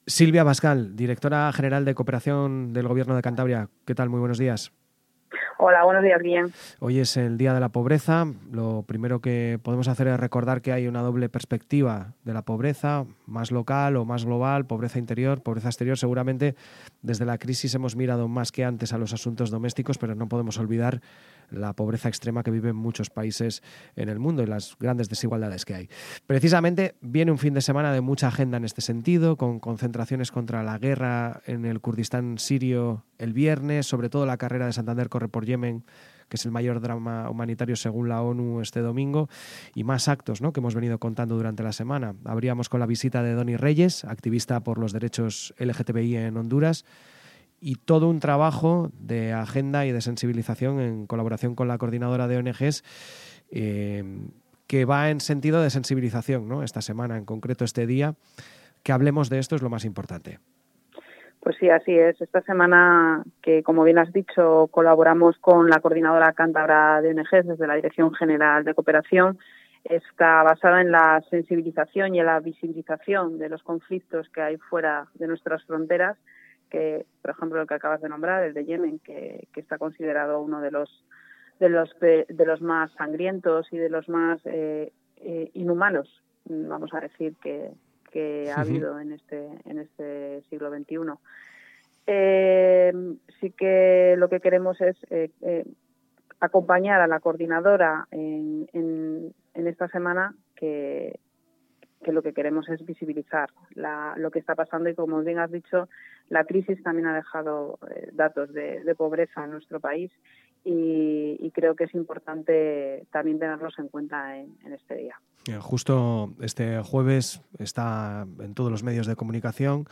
Así lo admitía en una entrevista en EL FARADIO con motivo de esta conmemoración Silvia Abascal, la directora general de Cooperación al Desarrollo del Gobierno de Cantabria, departamento que esta legislatura cuenta con un área específica adscrita a la Consejería de Cultura, Universidades, Igualdad, Cultura y Deporte, a cuyo frente está el vicepresidente Pablo Zuloaga,